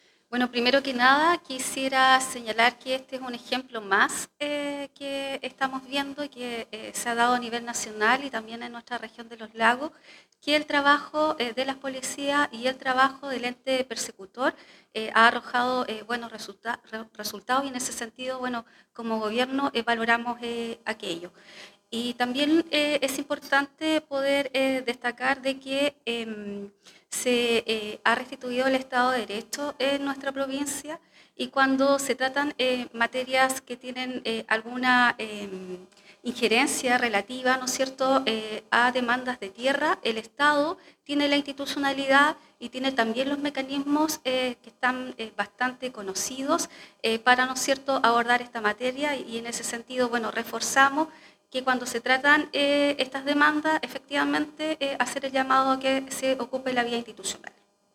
La Delegada Presidencial Provincial de Osorno, Claudia Pailalef, valoró el trabajo de las fuerzas policiales, destacando la importancia de estas acciones como un ejemplo del compromiso del Estado en la lucha contra el crimen organizado.